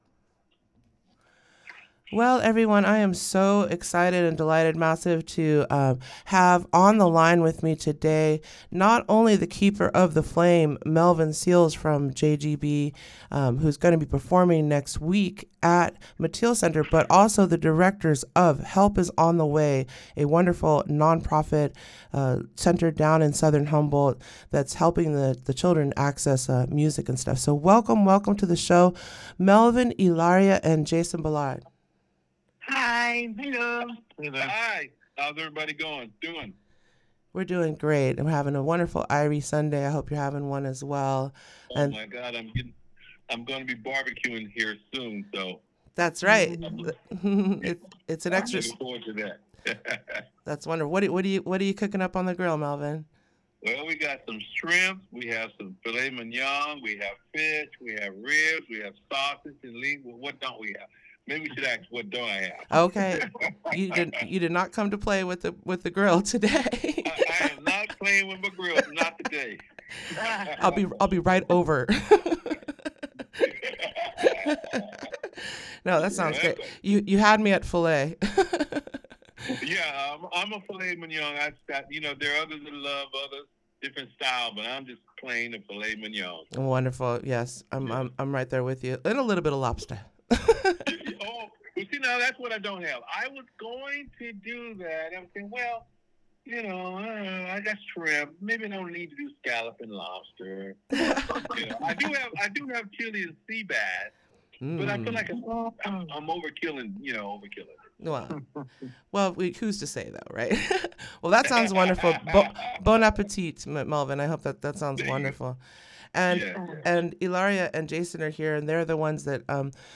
You can hear that interview below and/or scroll all of Friday’s happenings in your Lowdown farther down. (AUDIO) Melvin Seals on KWPT